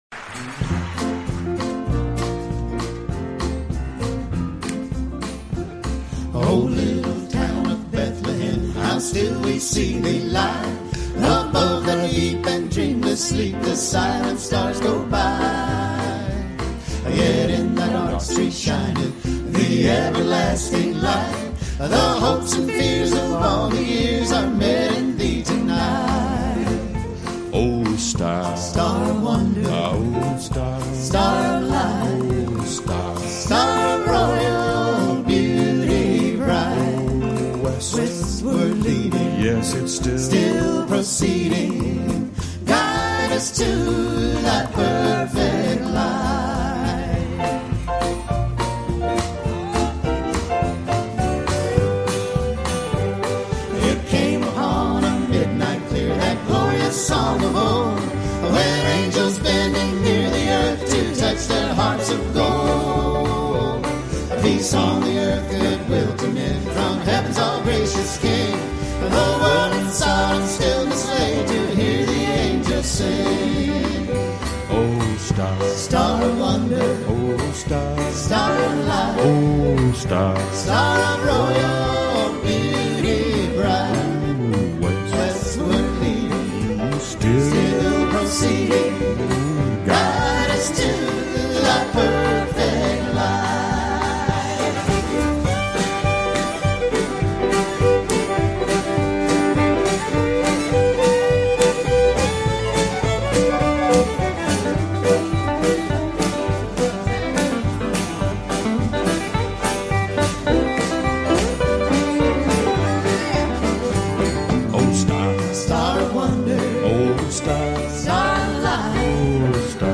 Originally broadcast on Christmas Eve 1994, A Prairie Home Christmas is a delightful compilation of all-time-favorite highlights from past holiday broadcasts of Garrison Keillor’s A Prairie Home Companion.
Narrator
Garrison Keillor & Full Cast